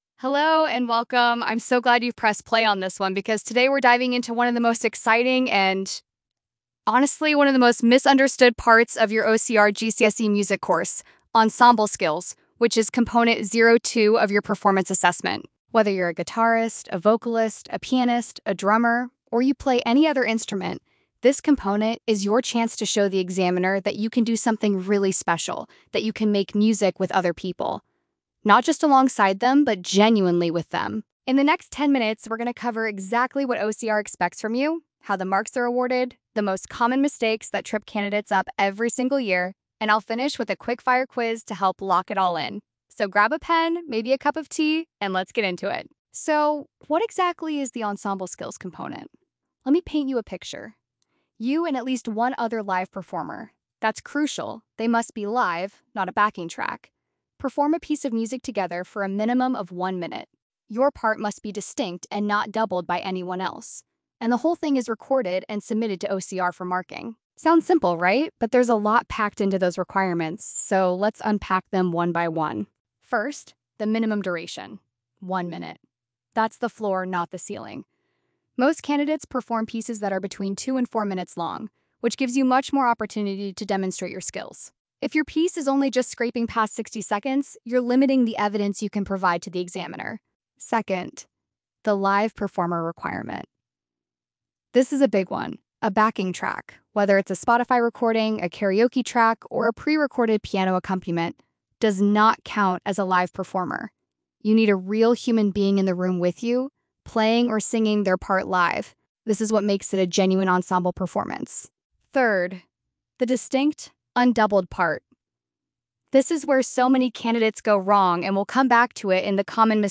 ensemble_skills_podcast.mp3